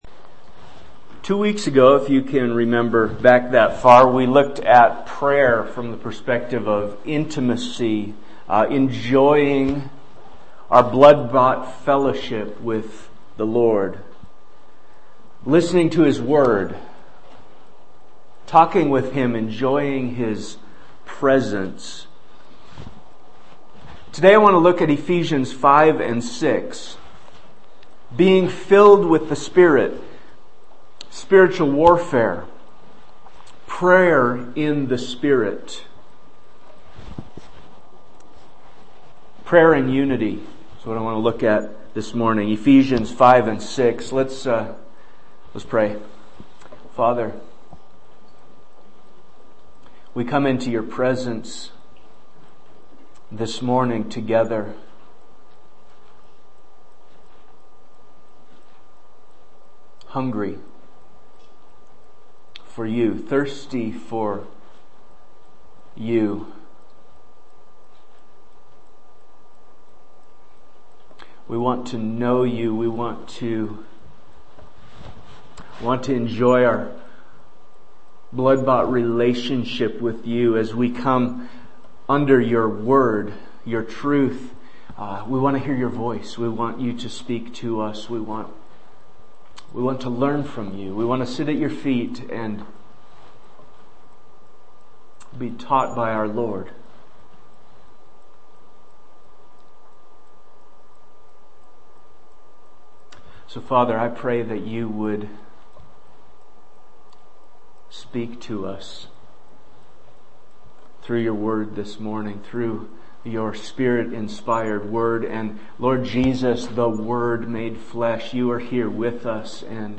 Sermon Manuscript